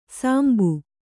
♪ sāmbu